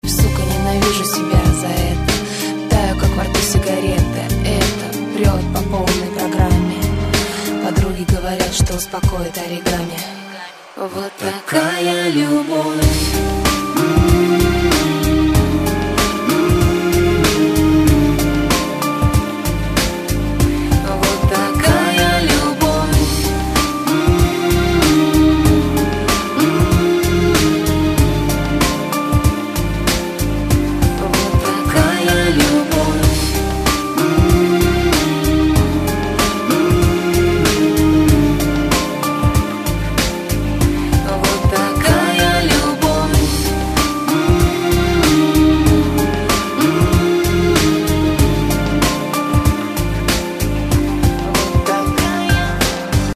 • Качество: 128, Stereo
женский вокал
грустные
спокойные
медленные
медляк